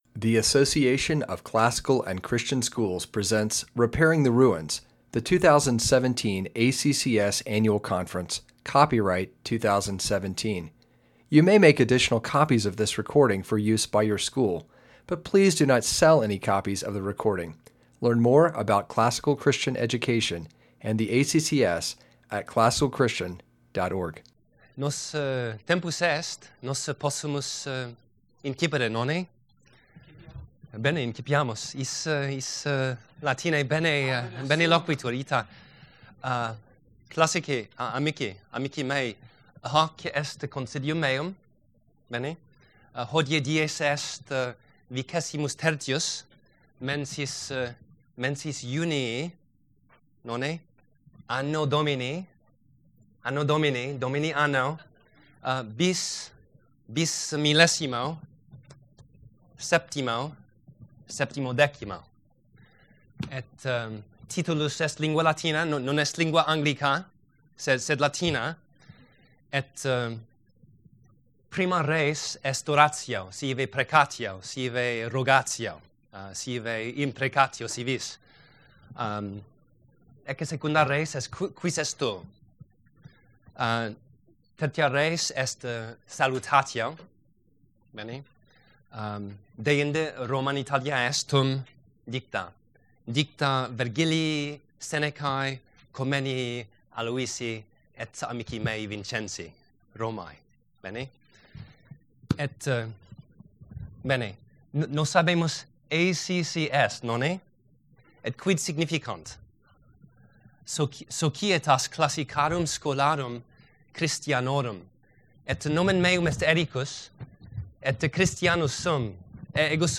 2017 Workshop Talk | 0:52:55 | K-6, Latin, Greek & Language
The workshop will assist Latin teachers by demonstrating how to start a beginning Latin class, in which Latin is used as the language of instruction, on the first day of school. Speaker Additional Materials The Association of Classical & Christian Schools presents Repairing the Ruins, the ACCS annual conference, copyright ACCS.